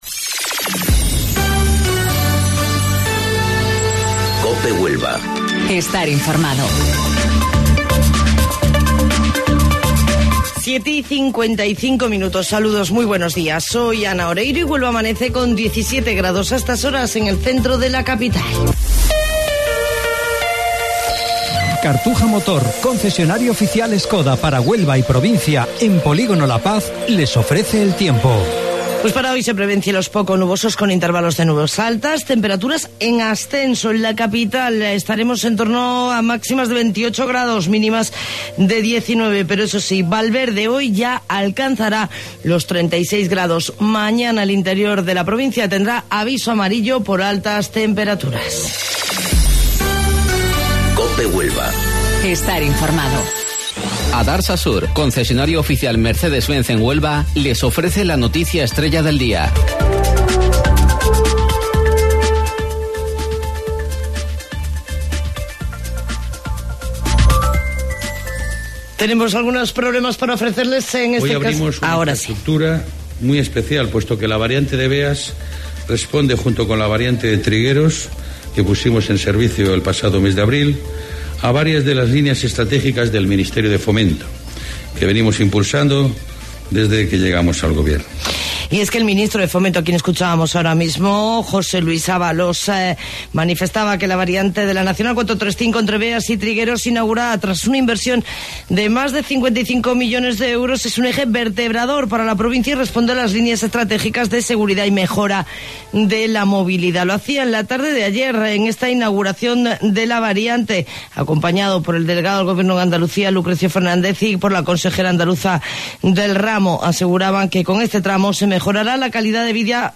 AUDIO: Informativo Local 07:55 del 10 de Julio